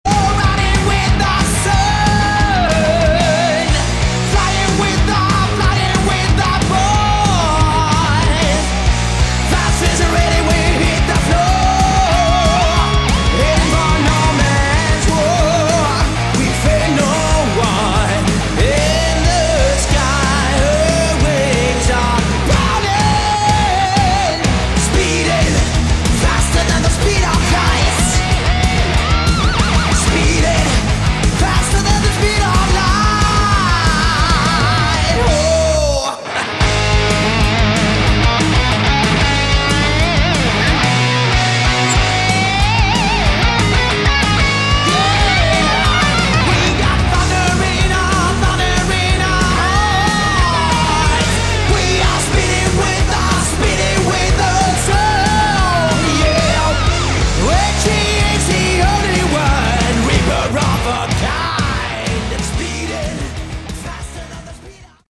Category: Hard Rock / Melodic Metal
vocals
lead guitars
bass
drums